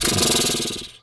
horse_2_breath_02.wav